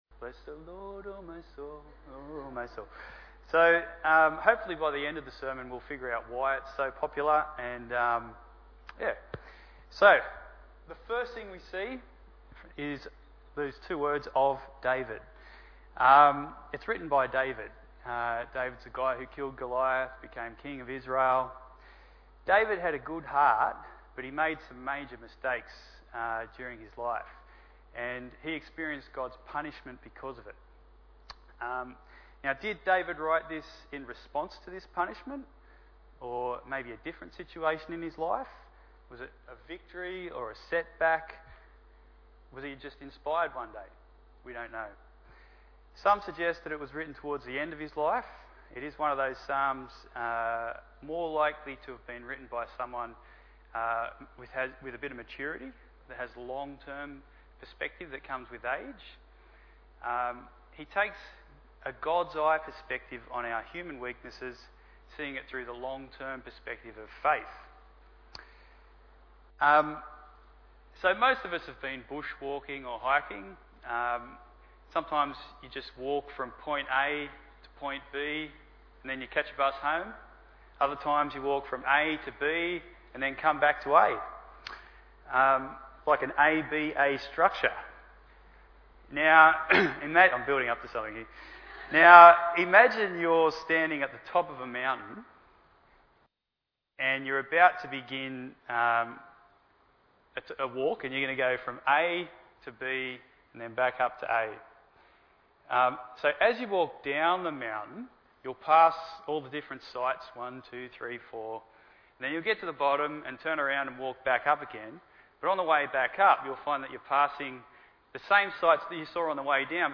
11/03/2018 Psalm 103 Preacher